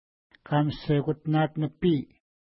Pronunciation: ka:məsekutna:t-nəpi: